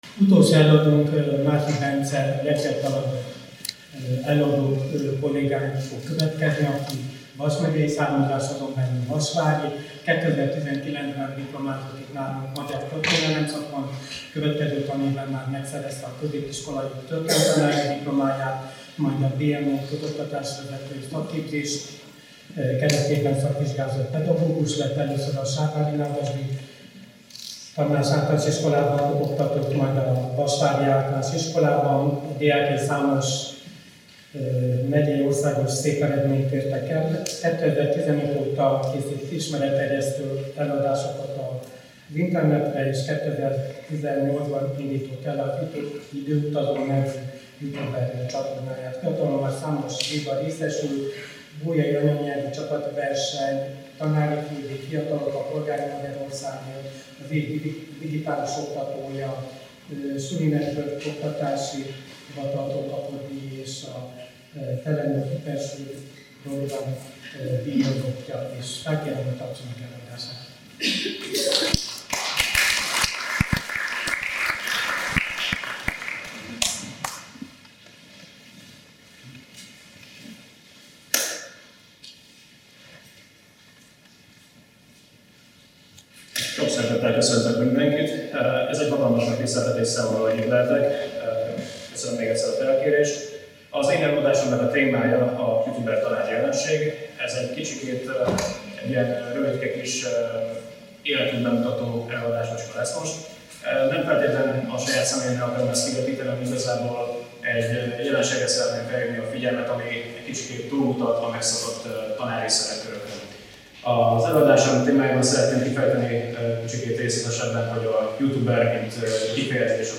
Rendezvény a szombathelyi történelem szakos tanárképzés indulásának és a Történelem Tanszék alapításának 50 éves jubileuma alkalmából.
Előadások, konferenciák